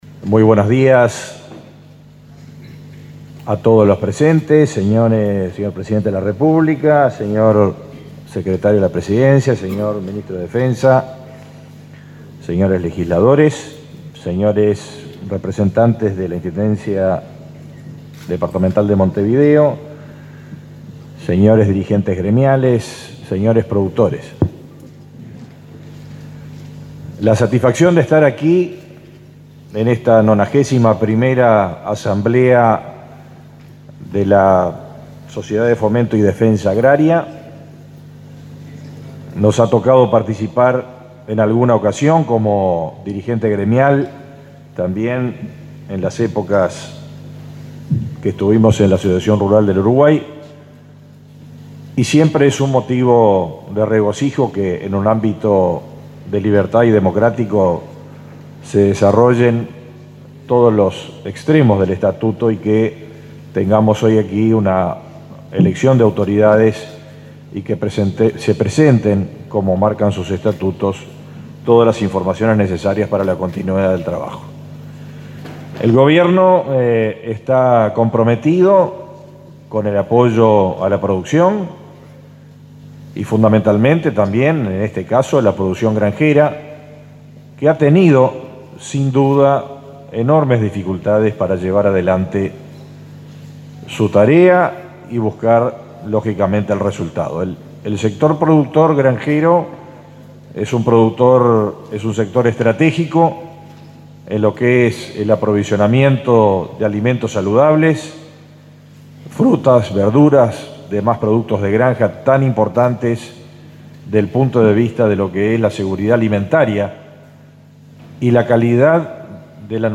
Palabras del ministro de Ganadería, Fernando Mattos
El ministro de Ganadería, Fernando Mattos, participó este domingo 5 de la Asamblea Anual de la Sociedad de Fomento y Defensa Agraria.